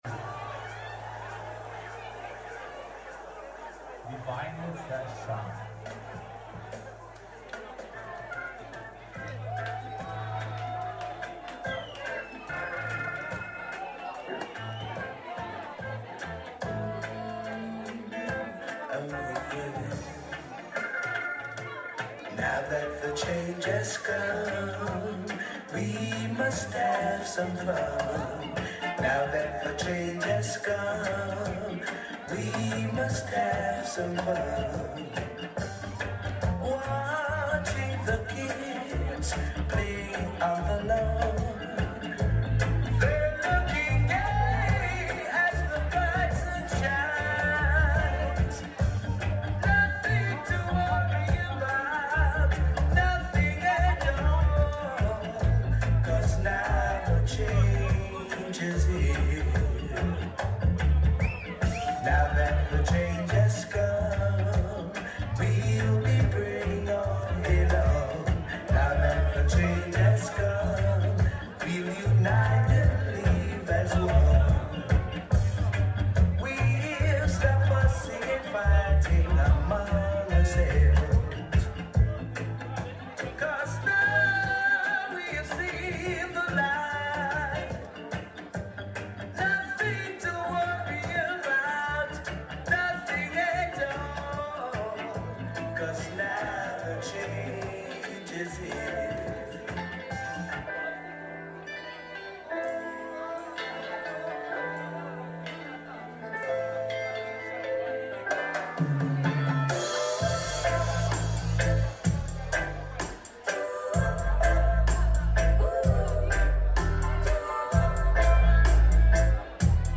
in session